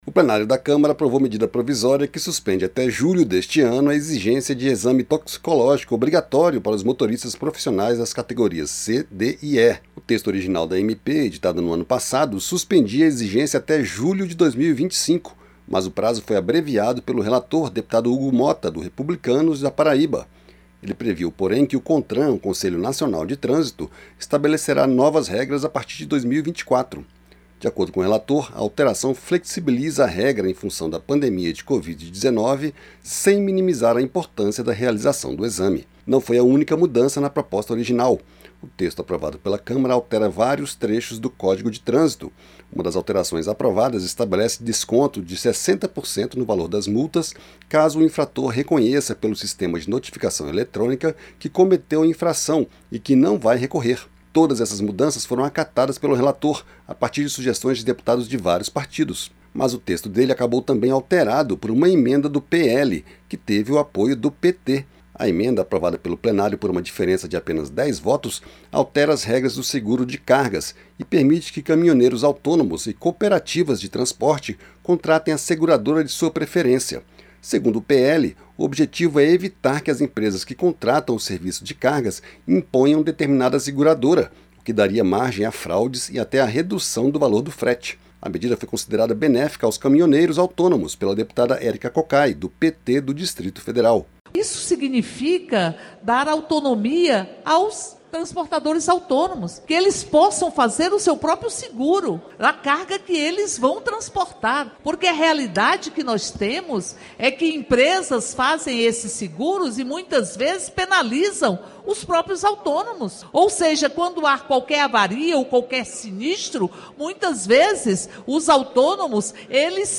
Câmara aprova MP que suspende até julho a exigência do exame toxicológico para motoristas profissionais e prevê desconto de até 60% para multas de trânsito - Radioagência - Portal da Câmara dos Deputados